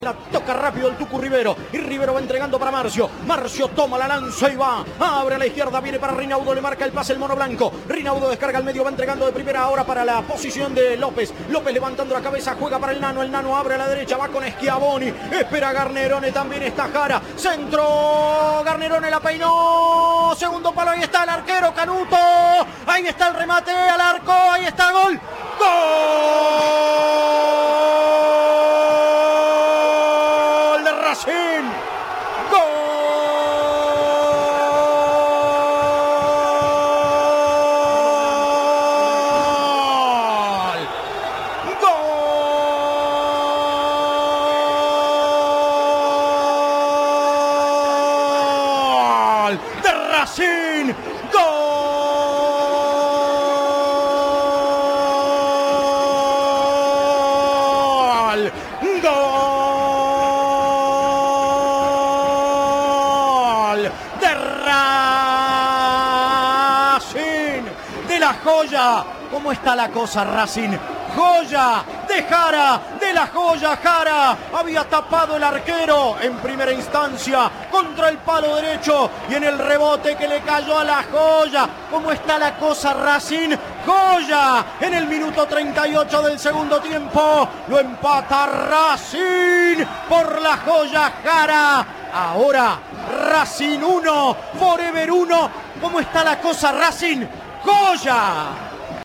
1. Audio. Relato del penal que le dio el triunfo a Chaco For Ever ante Racing de Córdoba